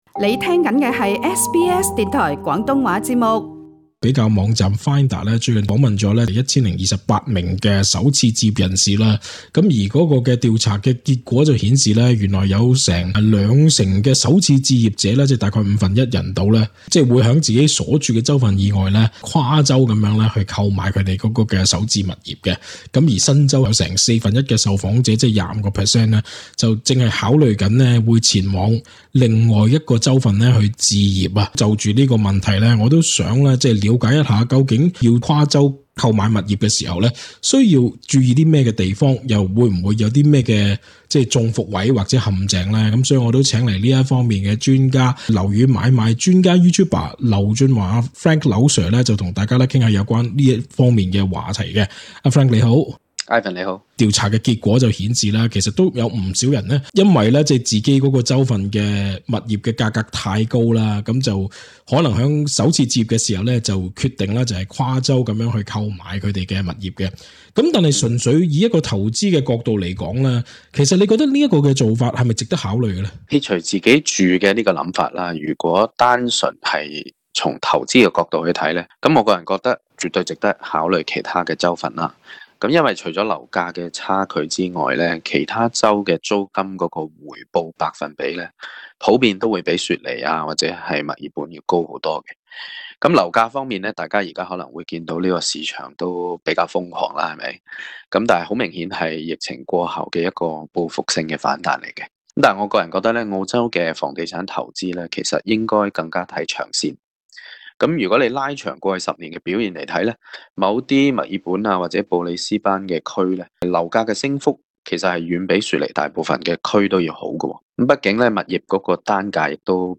請收聽本台的足本訪問錄音。